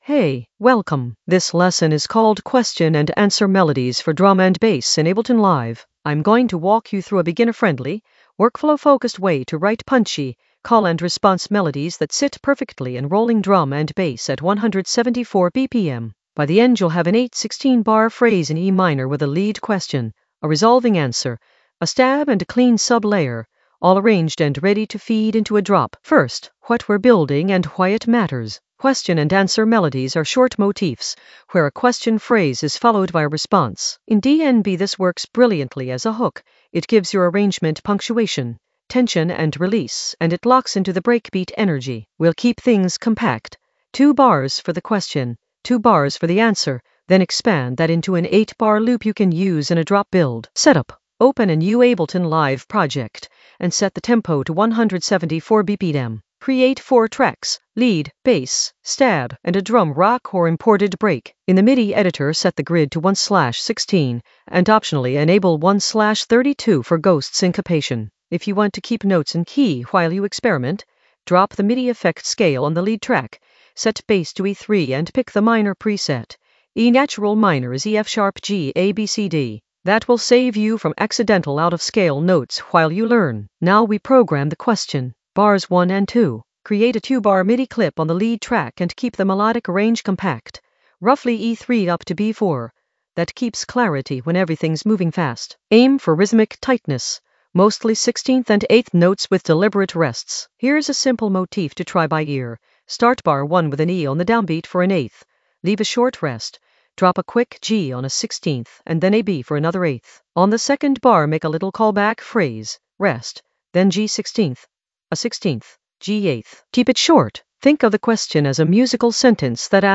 An AI-generated beginner Ableton lesson focused on Question and answer melodies in the Composition area of drum and bass production.
Narrated lesson audio
The voice track includes the tutorial plus extra teacher commentary.